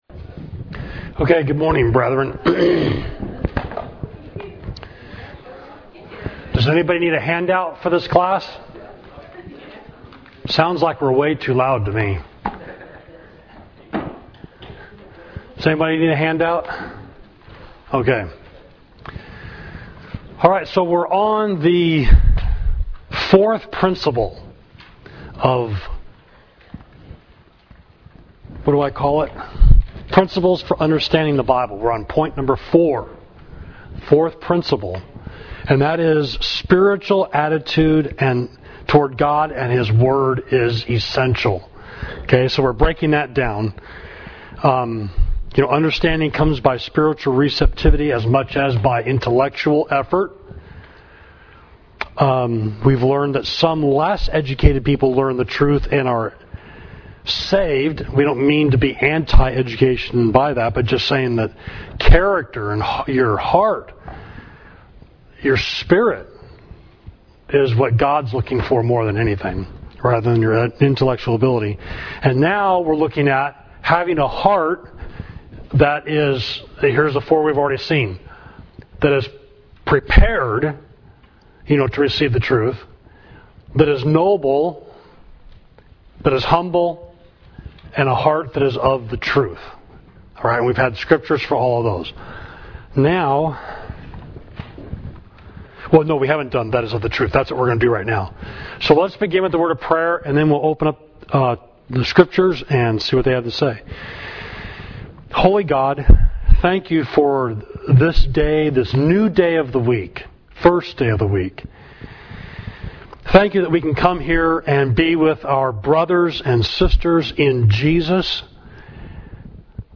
Class: Hermeneutics – Spiritual Attitude God and His Word Is Essential